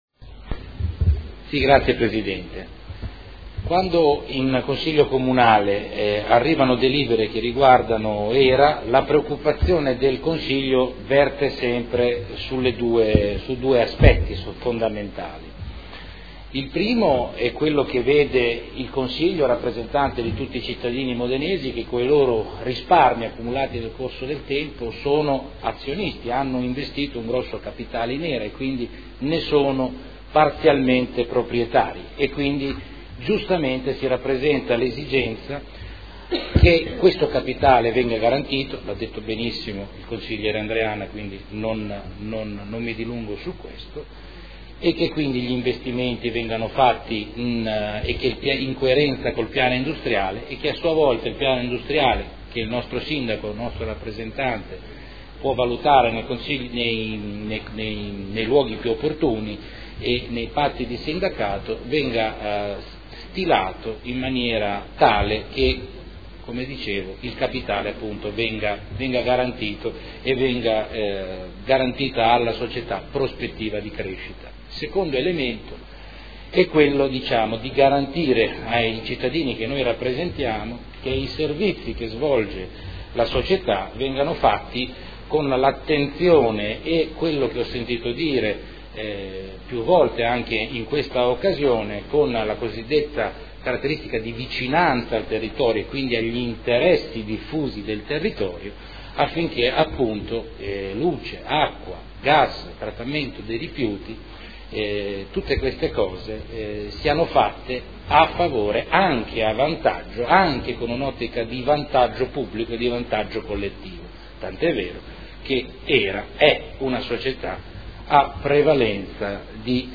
Seduta del 24 marzo. Proposta di deliberazione: Fusione per incorporazione di AMGA Azienda Multiservizi di Udine in Hera e modifiche allo Statuto Hera. Dibattito